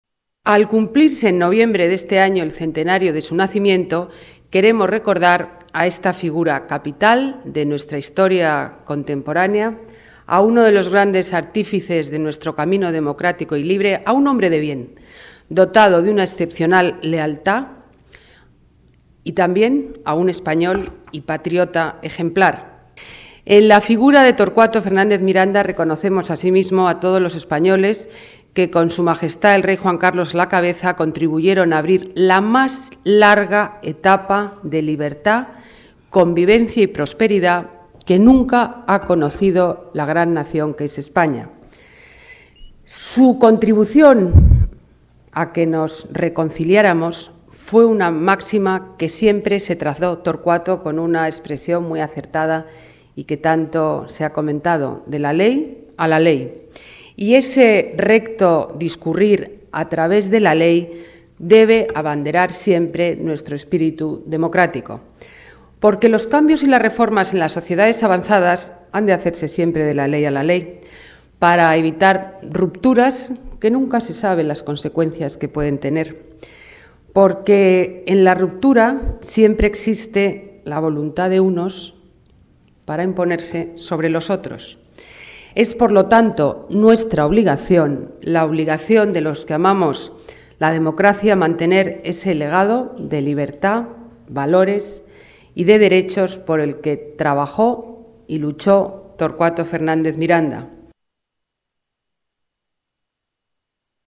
Nueva ventana:Declaraciones Ana Botella: Centenario Torcuato- Fernández Miranda